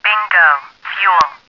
bingofuel.wav